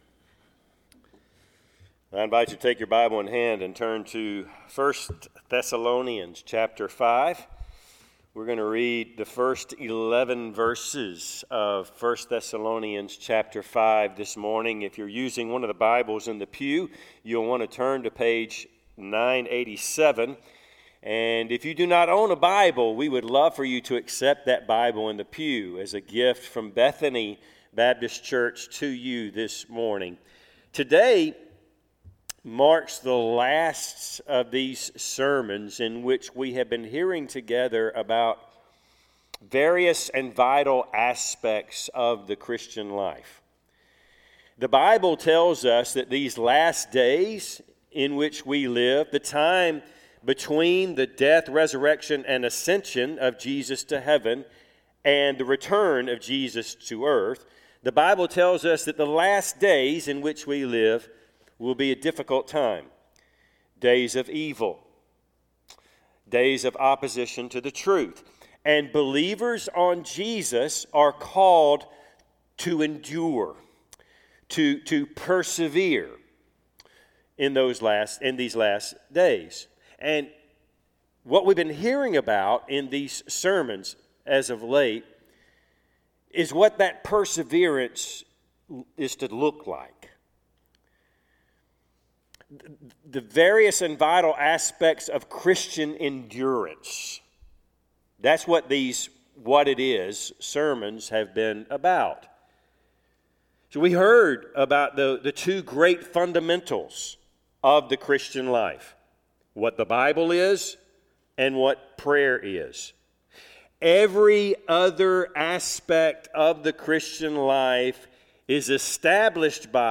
Service Type: Sunday AM Topics: Christian living , perseverance « Prayer